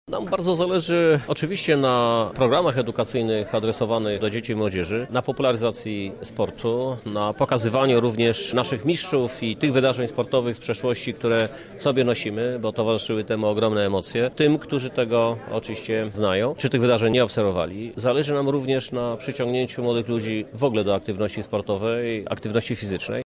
Centrum Historii Sportu ma swoją radę programową. Ma się zająć organizacją muzeum i doborem ekspozycji – mówi Krzysztof Żuk, prezydent Lublina.